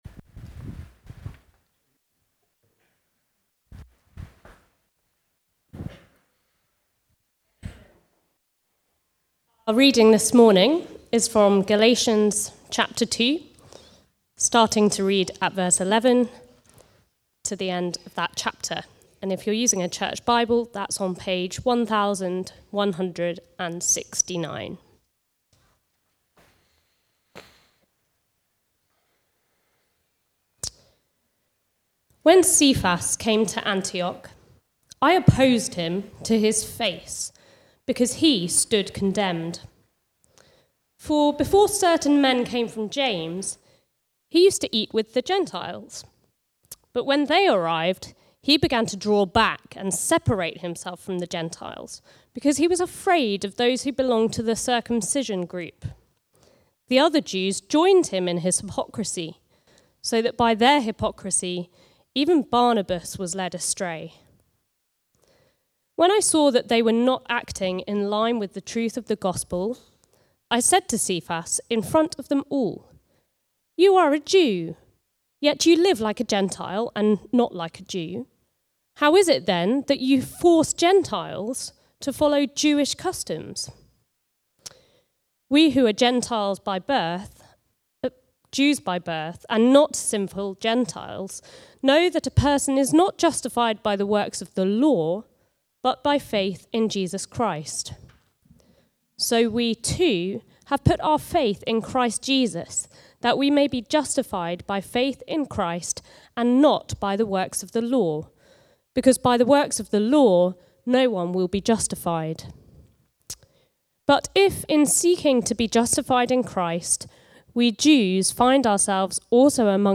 Preaching
The Gospel of Grace (Galatians 2:11-21) from the series Galatians - the Glorious Gospel. Recorded at Woodstock Road Baptist Church on 22 September 2024.